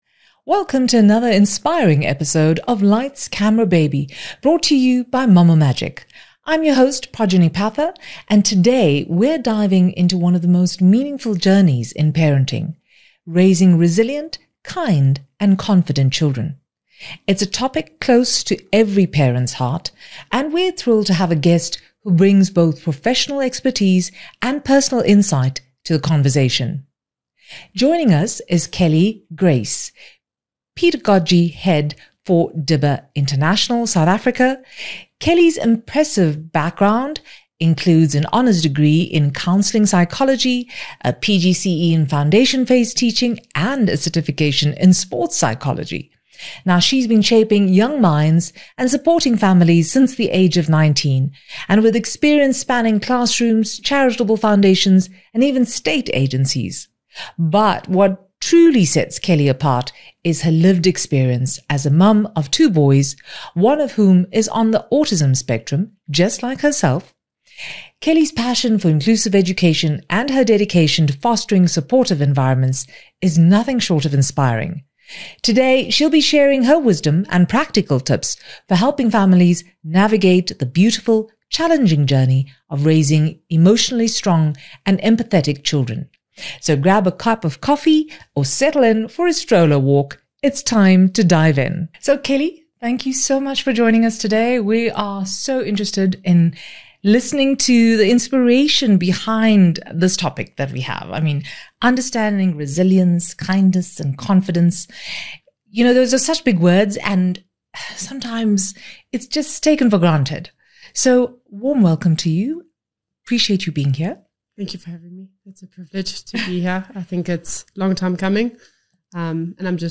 Tune in for a friendly, down-to-earth conversation that’s guaranteed to inspire you to make the most of your child’s early years!